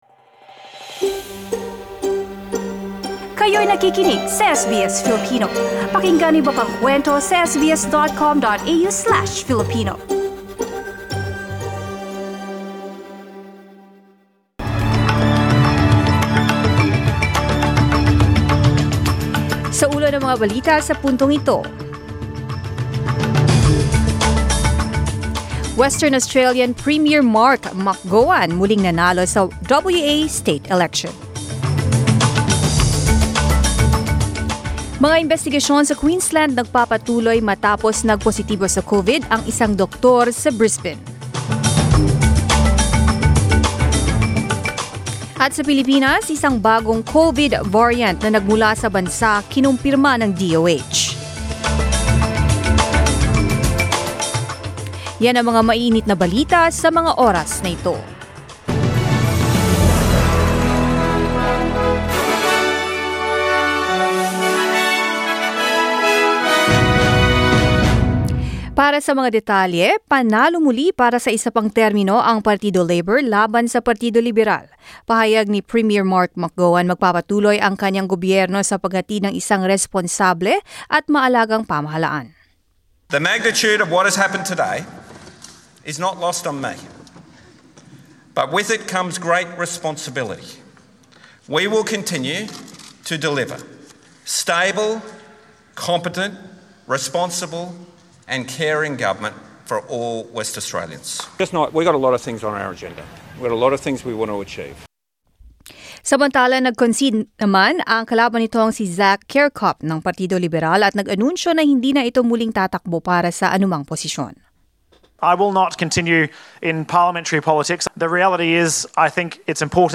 SBS News in Filipino, Sunday 14 March